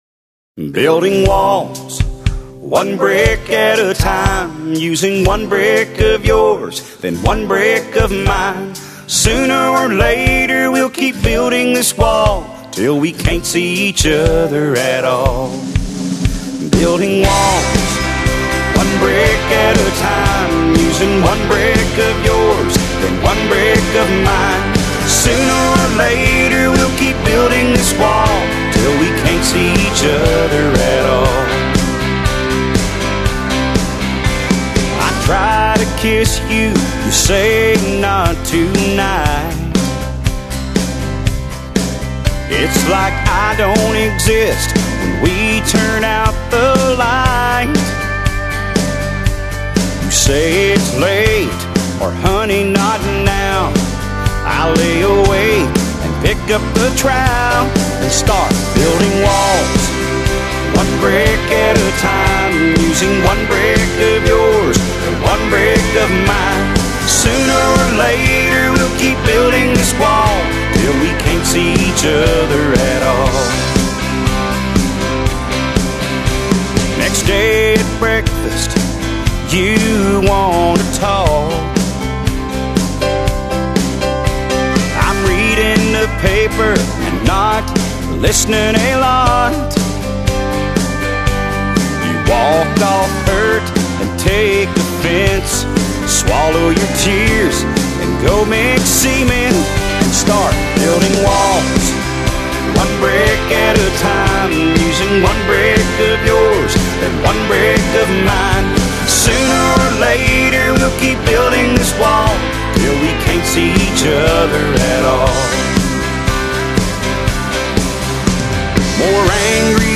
"Building Walls." (country)